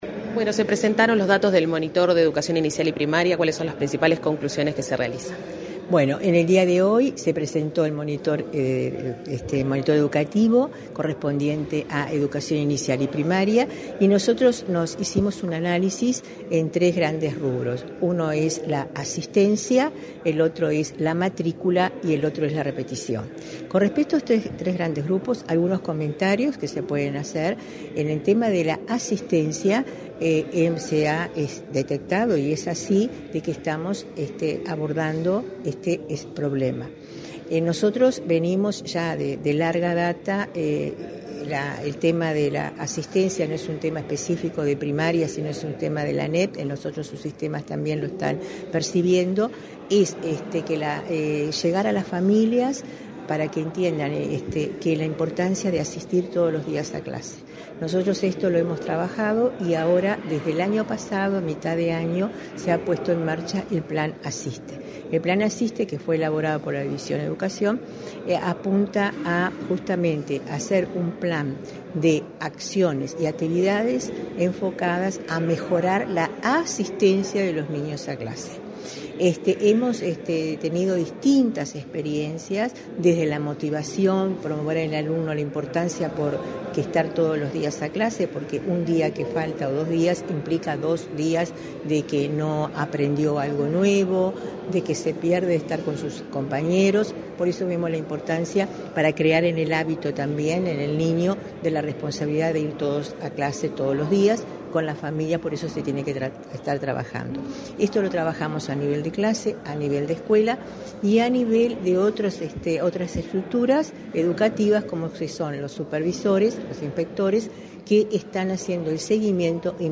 Entrevista a la directora general de Educación Inicial y Primaria, Olga de las Heras
de las heras monitor.mp3